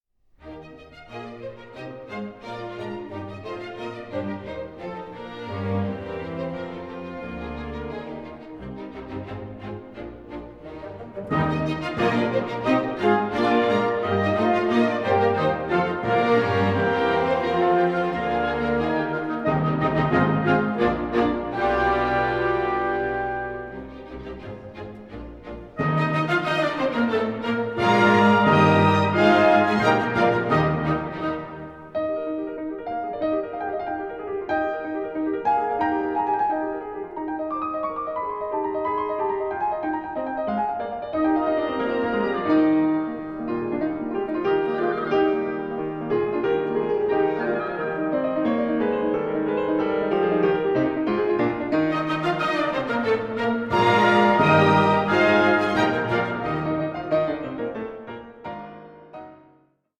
Piano Concerto No. 7 for 3 Pianos in F Major
Concerto for 2 Pianos and Orchestra in D Minor, FP61 (1932)
A SERIOUS YET PLAYFUL MUSICAL FAMILY PORTRAIT